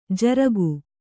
♪ jaragu